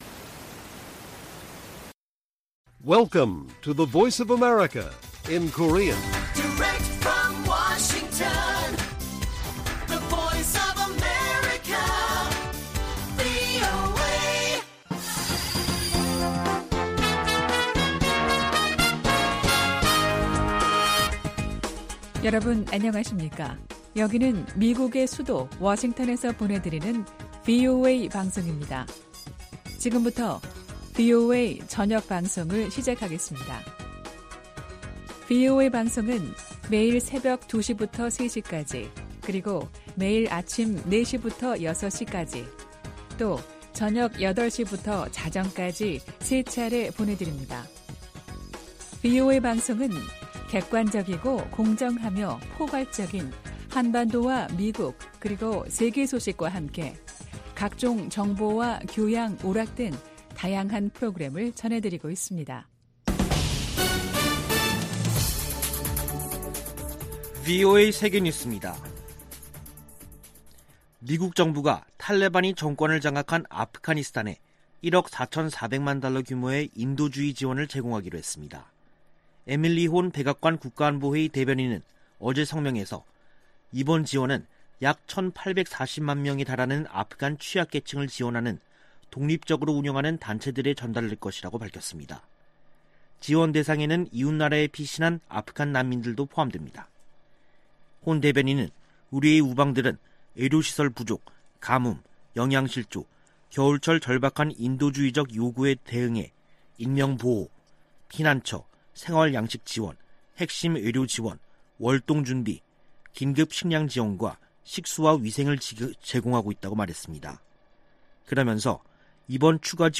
VOA 한국어 간판 뉴스 프로그램 '뉴스 투데이', 2021년 10월 29일 1부 방송입니다. 북한 신의주와 중국 단둥간 철도 운행이 다음달 재개될 가능성이 있다고 한국 국가정보원이 밝혔습니다. 유럽연합이 17년 연속 유엔총회 제3위원회에 북한 인권 상황을 규탄하는 결의안을 제출했습니다. 유엔총회 제1위원회에서 북한 핵과 탄도미사일 관련 내용 포함 결의안 3건이 채택됐습니다.